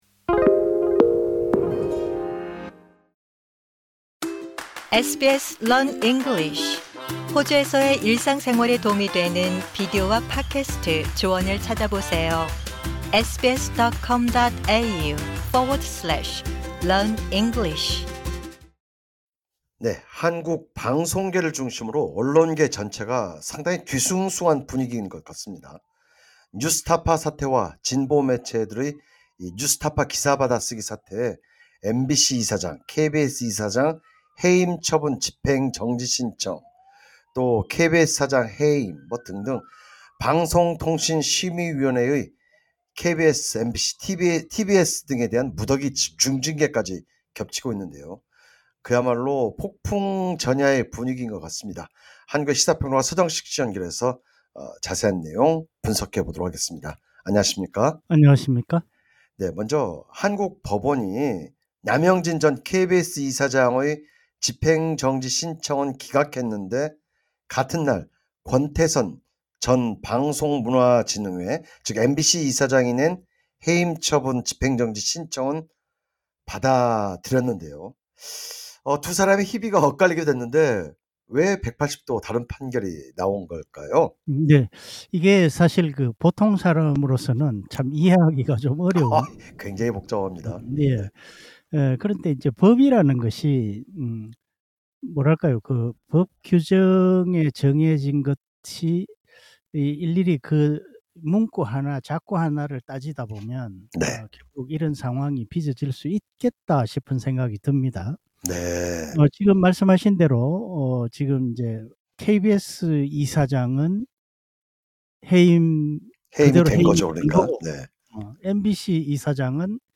해설: 시사평론가